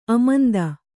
♪ amanda